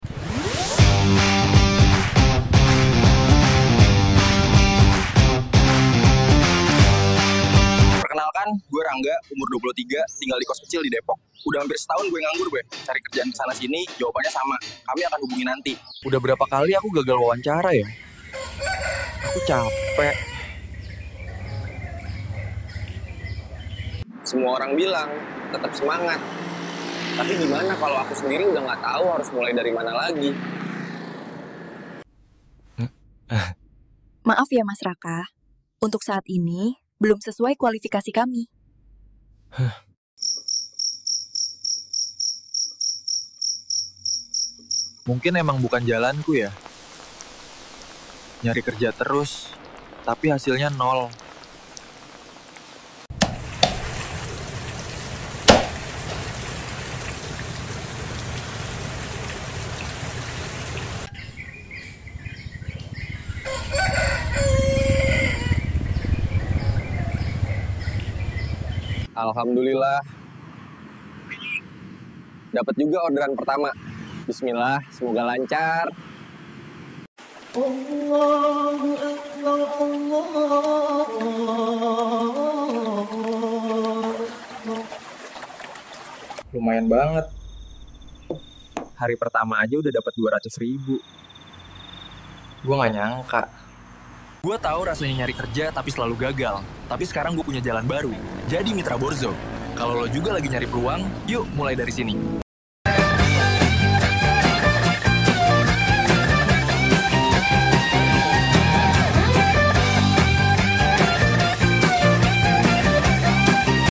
Film pendek ini bukan hanya tentang kurir. Ini tentang harapan, keberanian memulai, dan kesempatan kedua. 🎥 Sinematik realistis, dialog bahasa Indonesia, dan pesan yang menyentuh.